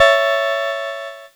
Cheese Chord 27-A4.wav